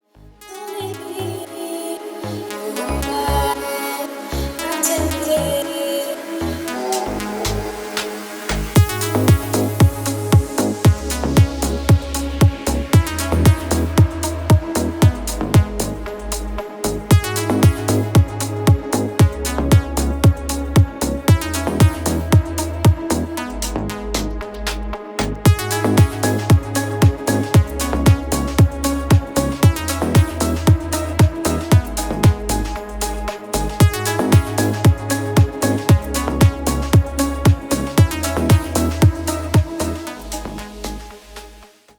• Качество: 320, Stereo
Стиль: deep house.